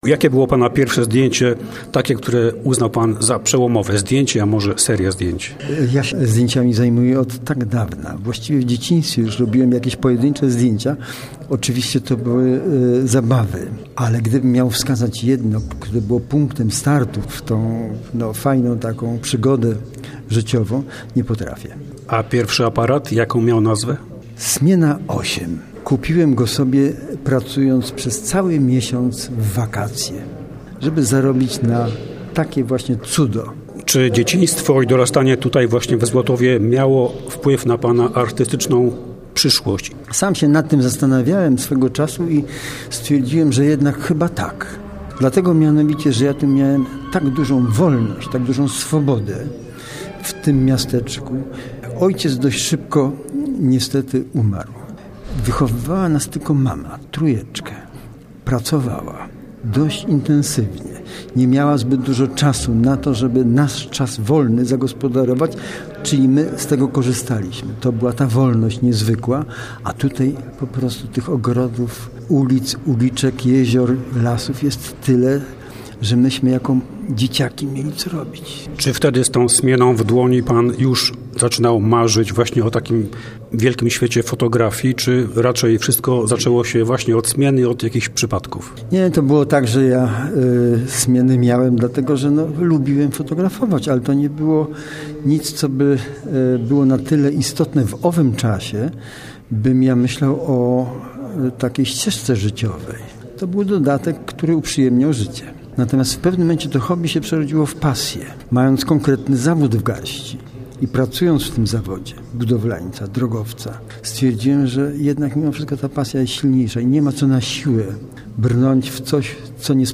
Tuż po wręczeniu honorowego obywatelstwa Złotowa z Andrzejem Świetlikiem rozmawiał